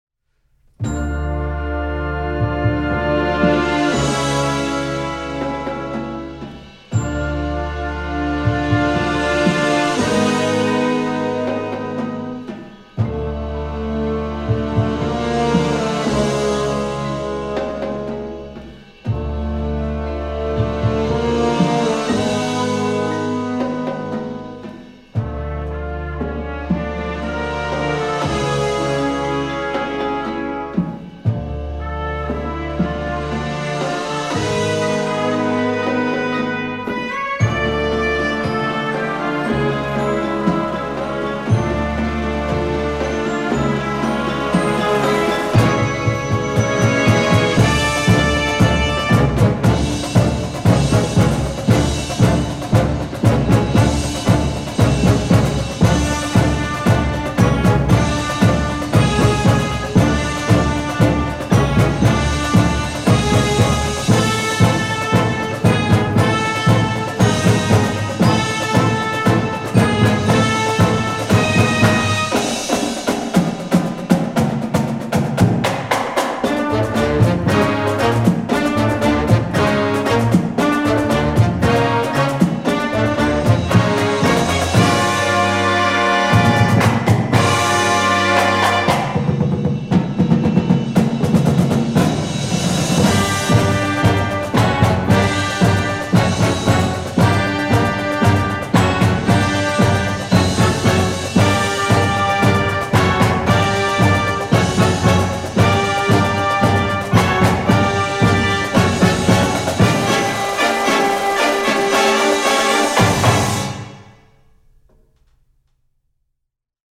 film/tv, movies